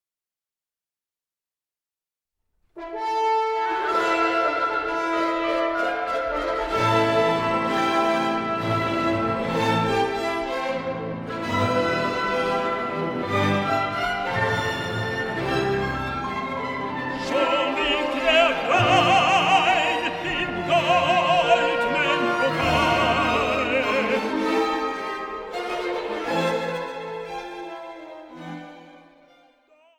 Mezzosopran
Tenor
Bariton
Kammermusik-Fassung
Allegro pesante